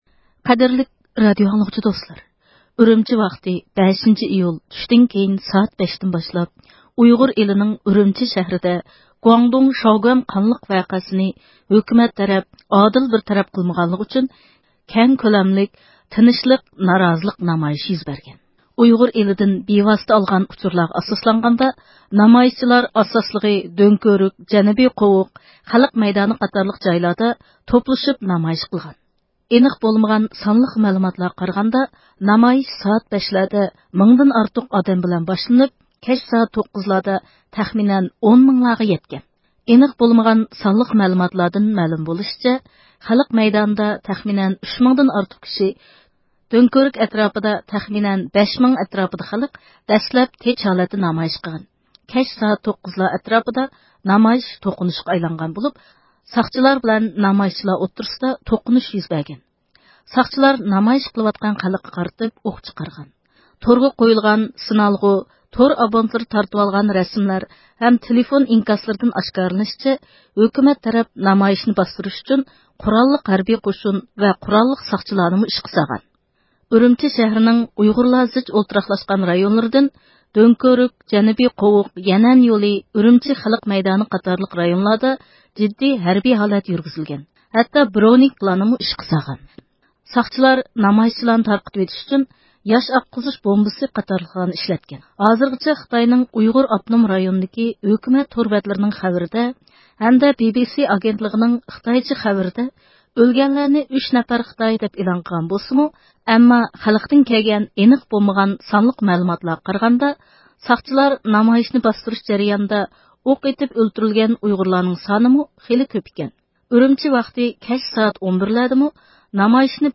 ئۈرۈمچىدىكى 5- ئىيۇل نامايىشى ھەققىدە دۇنيانىڭ ھەرقايسى جايلىرىدىكى ئۇيغۇرلار رادىيومىزغا تېلېفون قىلىپ ئۆزلىرى بىلىدىغان ئەھۋاللارنى ئىنكاس قىلدى.